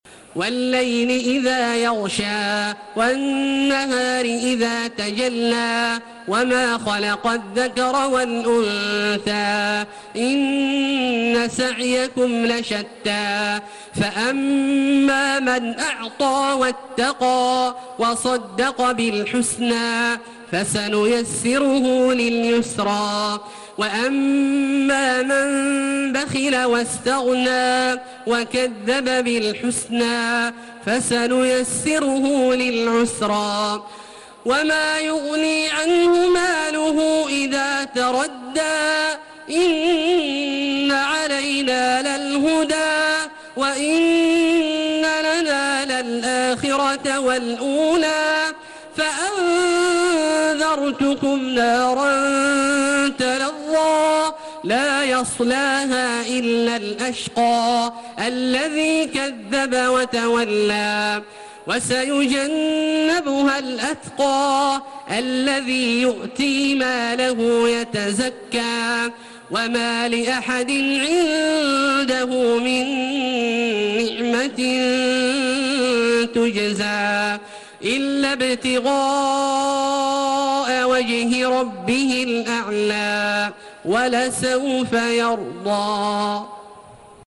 Surah Leyl MP3 by Makkah Taraweeh 1432 in Hafs An Asim narration.
Murattal